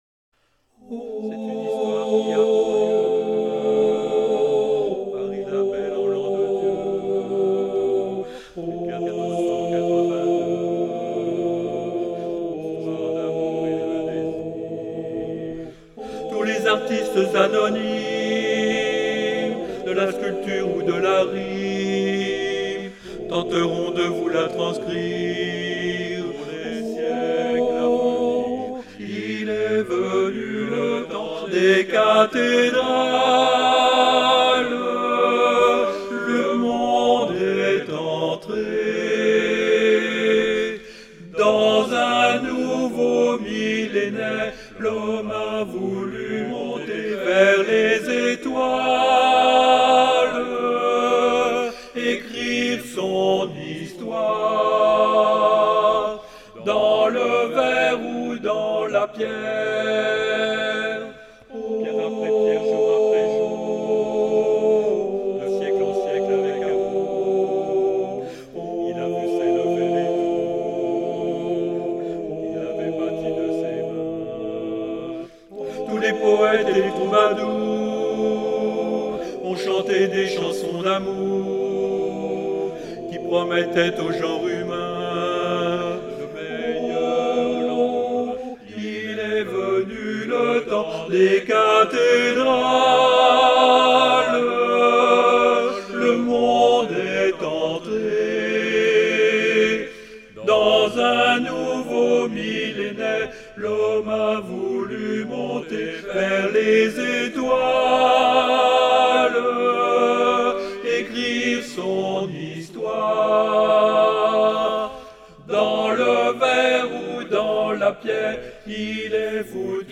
Soprano
à 4 voix